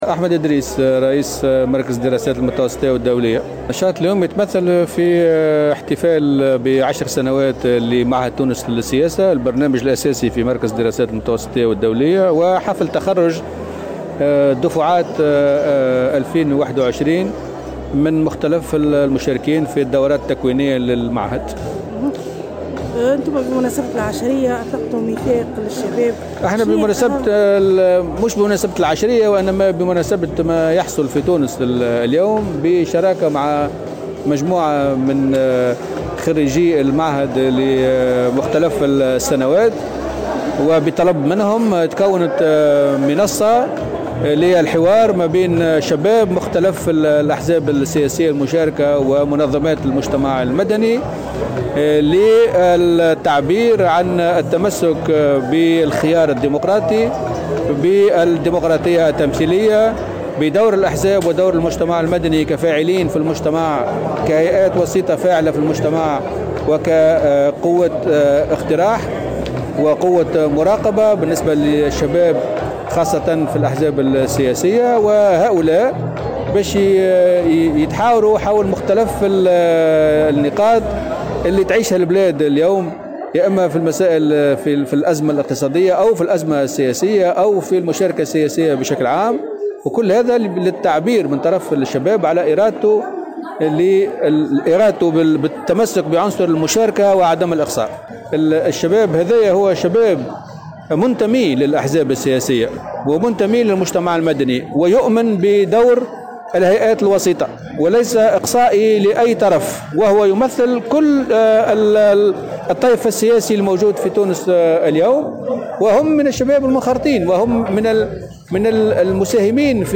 وجاء ذلك خلال ندوة صحفية، على هامش حفل استقبال، أقيم اليوم السبت بالعاصمة، كرّم فيها مركز الدراسات المتوسطية والدولية مجموعة من الشباب الناجحين والحائزين على ال"ديبلوم " .